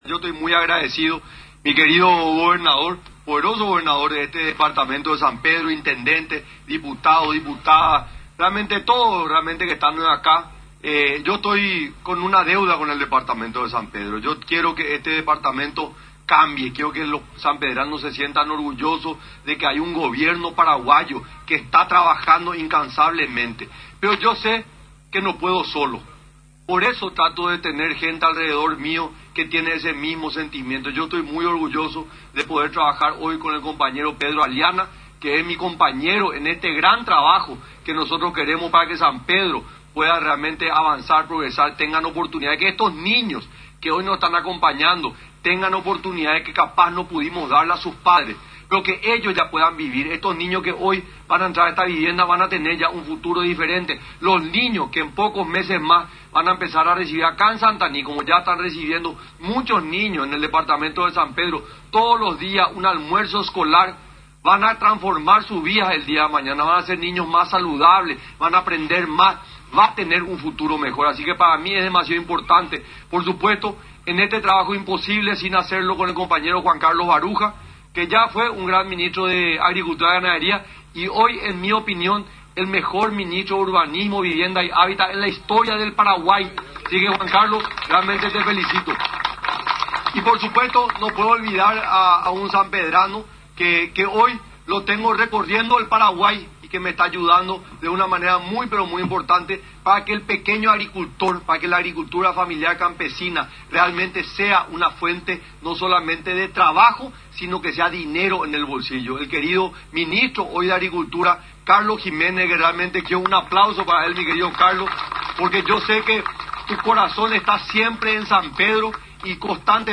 Dicha jornada de trabajo encabezó el propio presidente, Santiago Peña, junto con el vicepresidente, Pedro Alliana y autoridades de la región, que lideraron las inauguraciones que benefician a los distritos de Santaní, Guayaibí, Capiibary y Yrybycuá, en el departamento de San Pedro.
EDITADO-15-PRESIDENTE-SANTIAGO-PENA.mp3